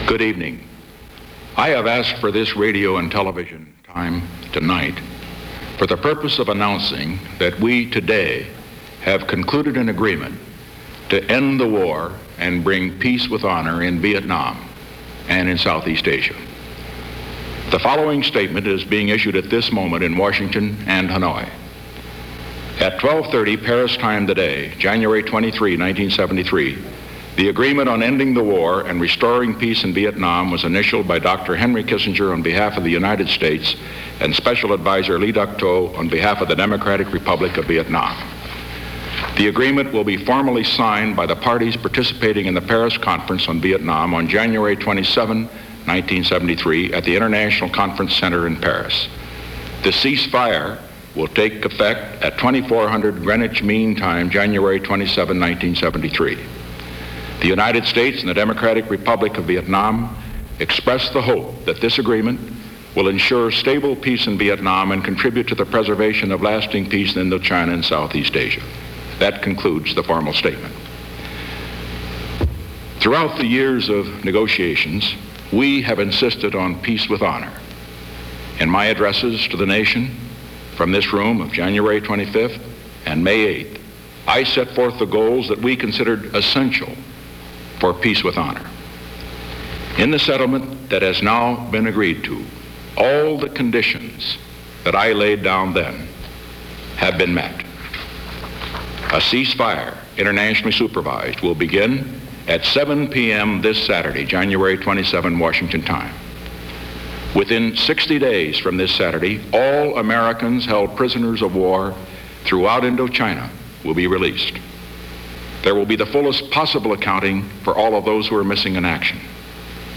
U.S. President Richard Nixon's cease-fire speech